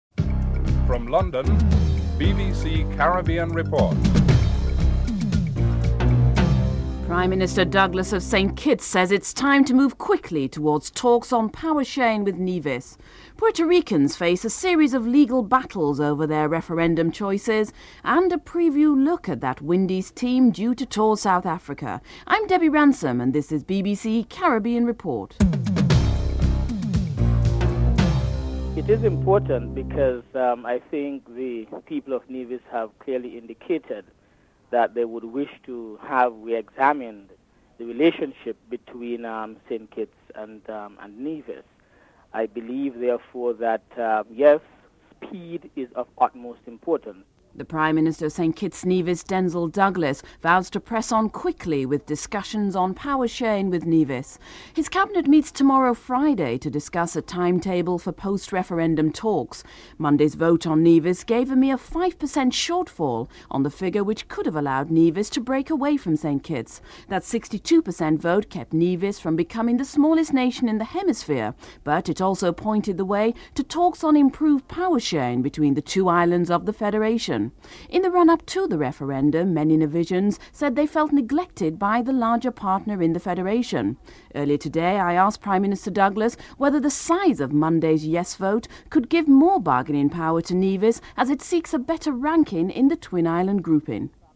Prime Minister Denzil Douglas and United States Department spokesman James Foley are interviewed (00:28-05:29)
Governor Tony Abbott is interviewed (07:39-08:26)